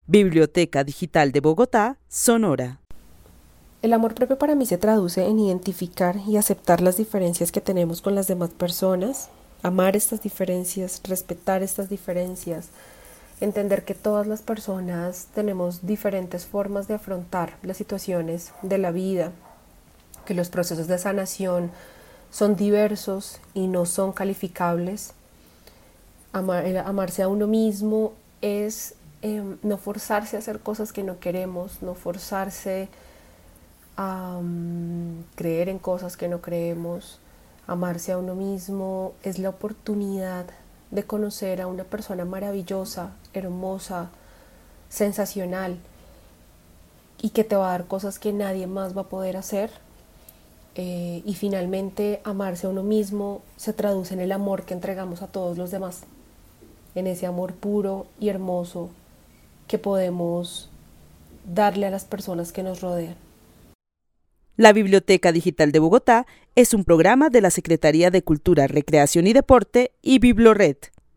Narración oral de una mujer que vive en la ciudad de Bogotá para la que el amor propio es identificar y aceptar las diferencias que tiene con otros. Destaca que el amor propio también es la oportunidad de conocer a una persona maravillosa.
El testimonio fue recolectado en el marco del laboratorio de co-creación "Postales sonoras: mujeres escuchando mujeres" de la línea Cultura Digital e Innovación de la Red Distrital de Bibliotecas Públicas de Bogotá - BibloRed.